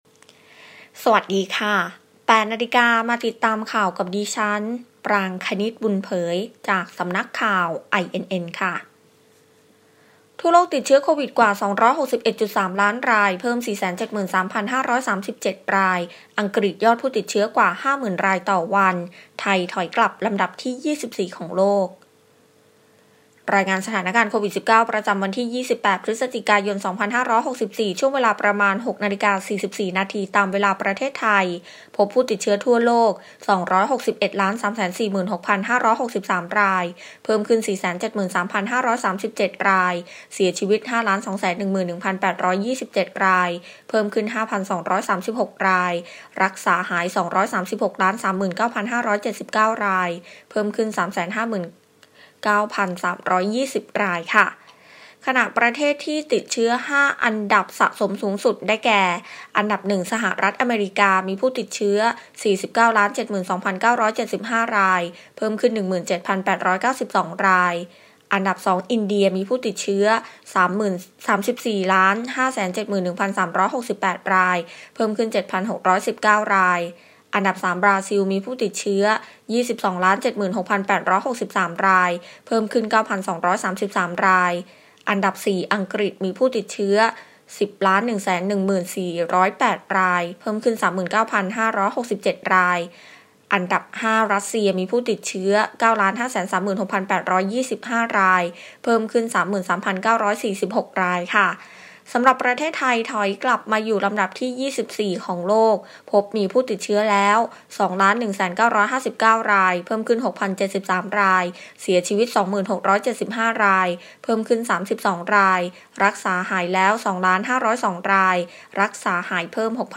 ข่าวต้นชั่วโมง 08.00 น.